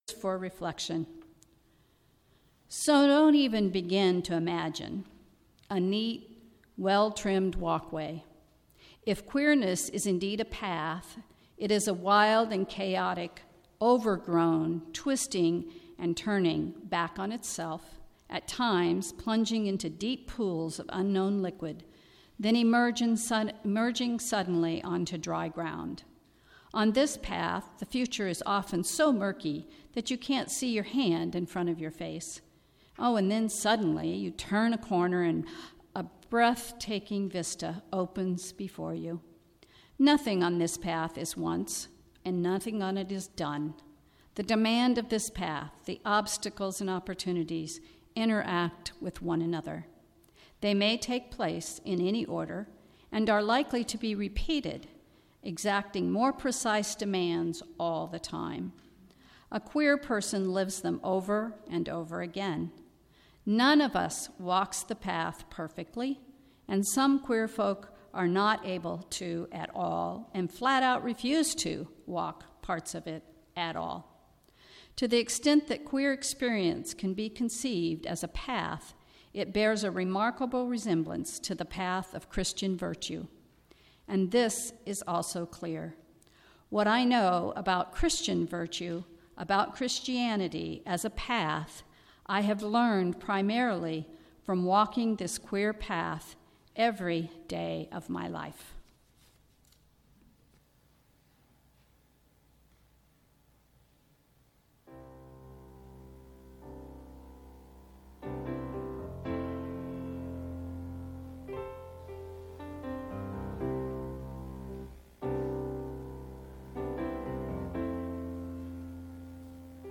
The Words of Reflection and message will focus on the history of the LGBTQ Pride Celebration. We will discuss the effect of heterosexual and cisgender privilege through the sharing of poetry and personal events with an opportunity for each of us to examine our own experiences of privilege. Service for All Ages.